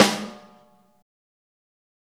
DrSnare36.wav